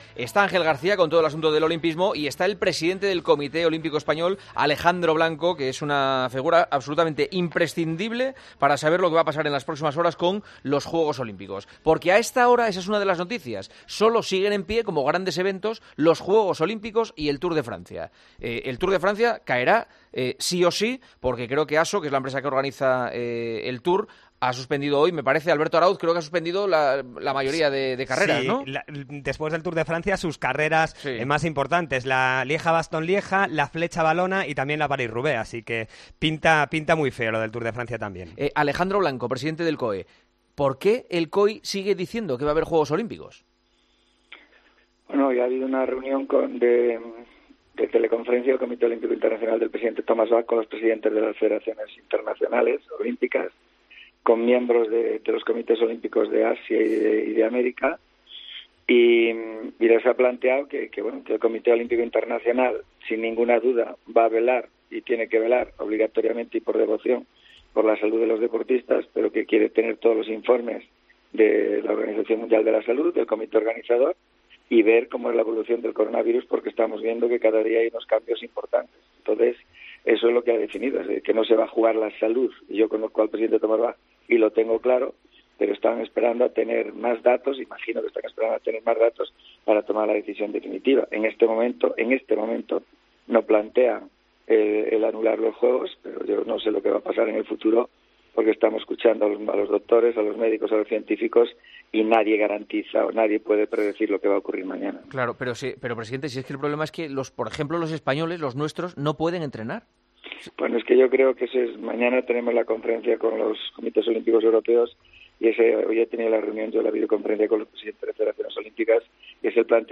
Entrevista al presidente del COE para hablar de una posible suspensión de los Juegos Olímpicos de este verano.